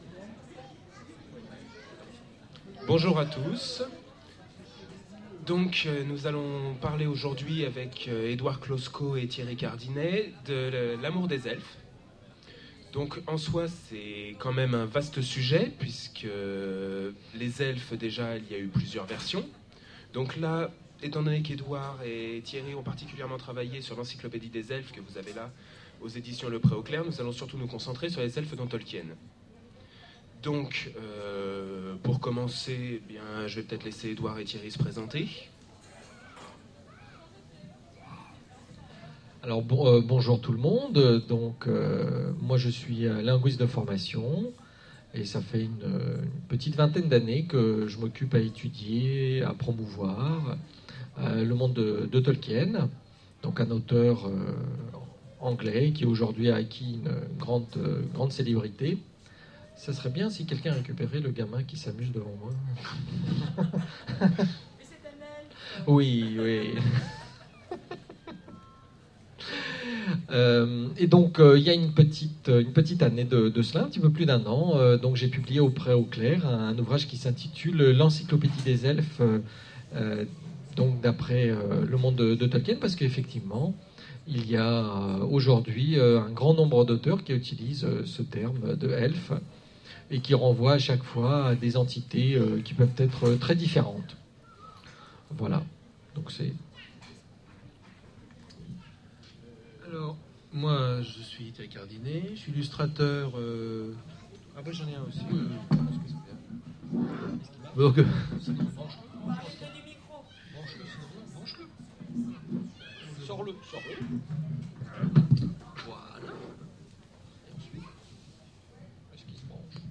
Voici l'enregistrement de la conférence Pour l'amour des Elfes lors du festival Zone Franche de Bagneux en février 2010